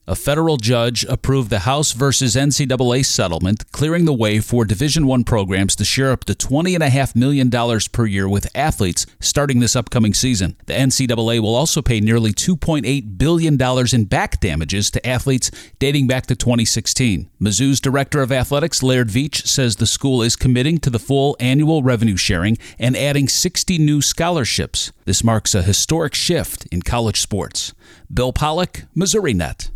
6-9-MoNET-House-vs-NCAA-voicer.mp3